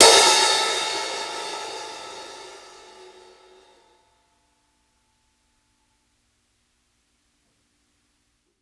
60s_OPEN HH_4.wav